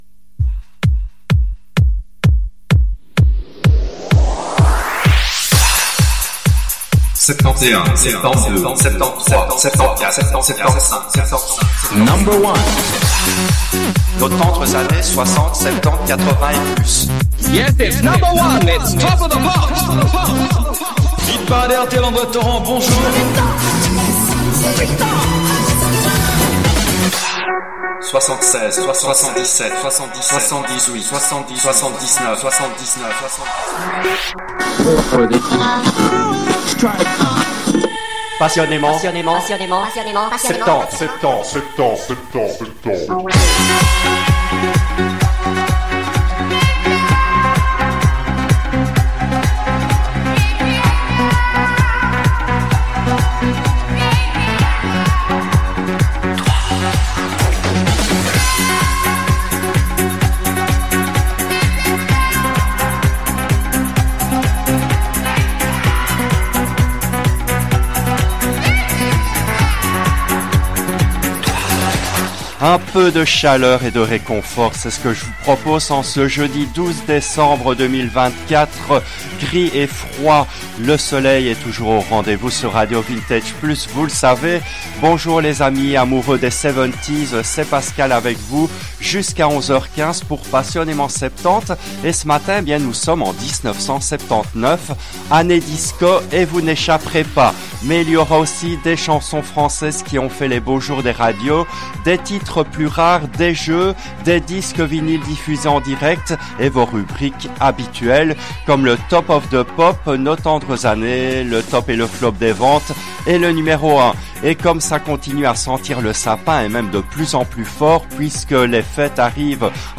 L’émission a été diffusée en direct le jeudi 12 décembre 2024 à 10h depuis les studios belges de RADIO RV+.